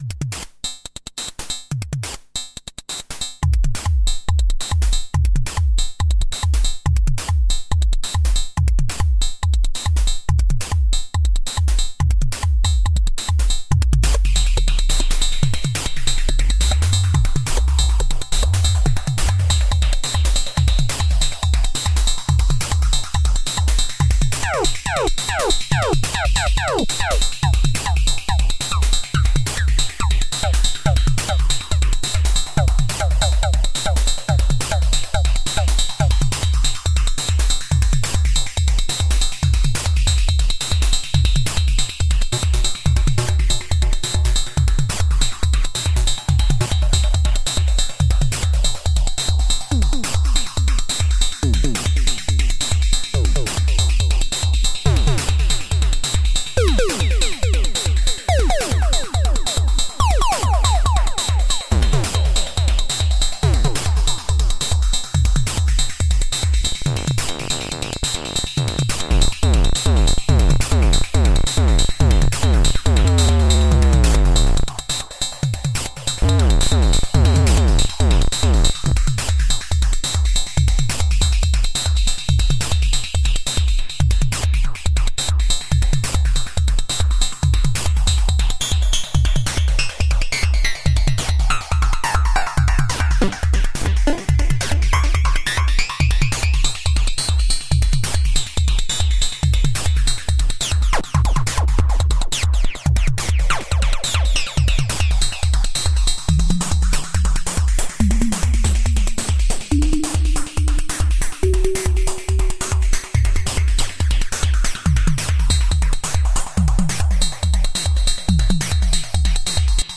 Most of these samples don't reflect the typical OPL3 sound, but should demonstrate the possibilites of the MIDIbox FM in conjunction with external Fx gear.
A FM drum groove with an external echo effect. Sound parameters are tweaked during the drums are playing to demonstrate some possible variations